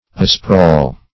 asprawl - definition of asprawl - synonyms, pronunciation, spelling from Free Dictionary Search Result for " asprawl" : The Collaborative International Dictionary of English v.0.48: Asprawl \A*sprawl"\, adv. & a. Sprawling.